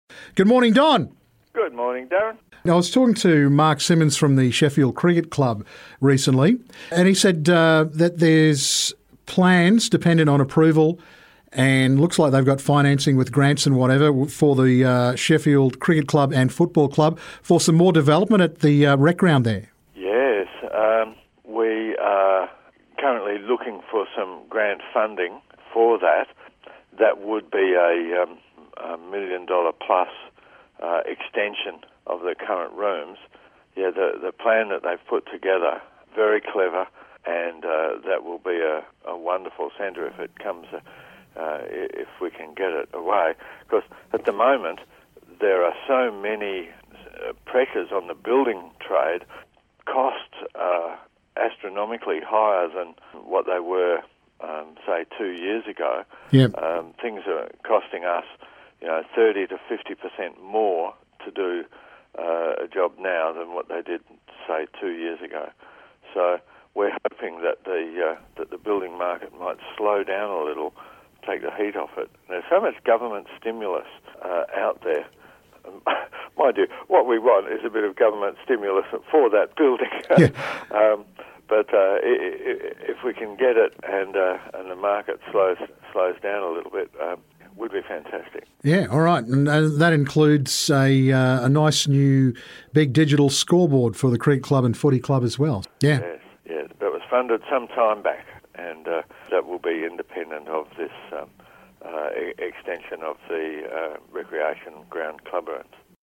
Deputy Mayor Don Thwaites tells us about plans for more improvements at the Sheffield Rec Ground.